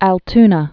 (ăl-tnə)